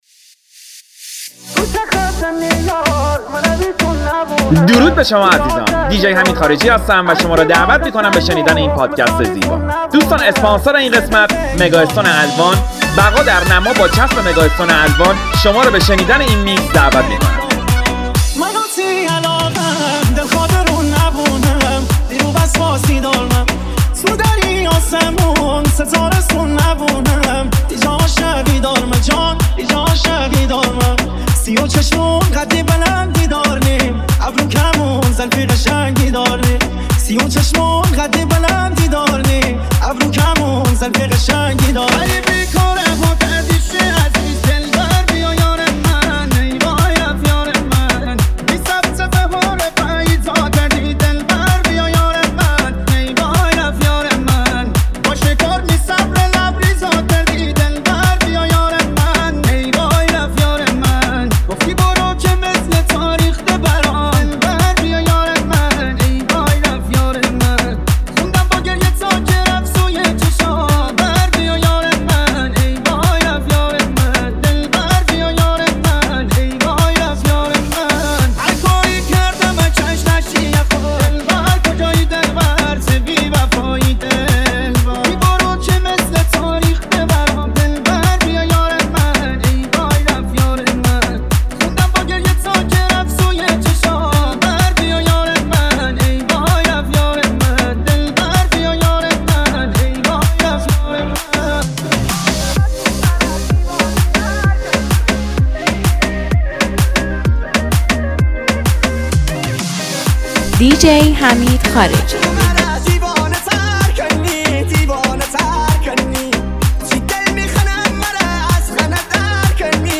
Remix Mazandarani Podcast